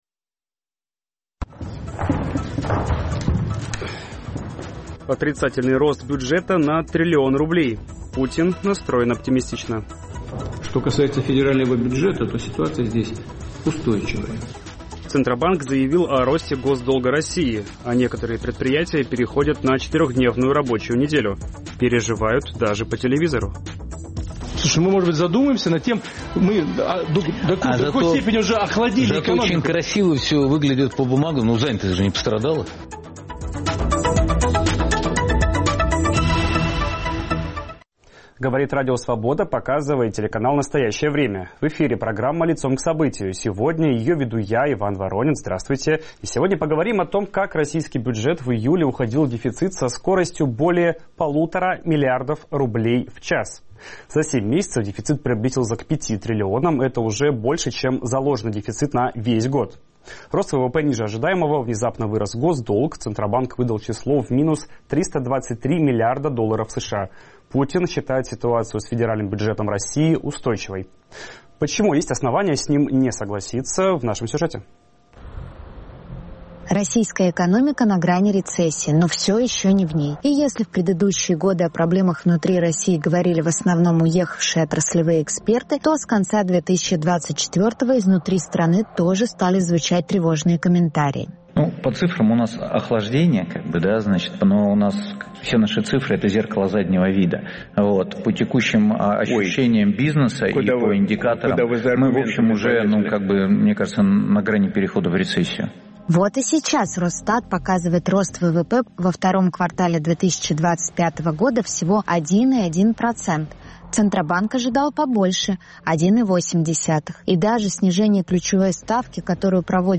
О ситуации в российской экономике говорим с экономистом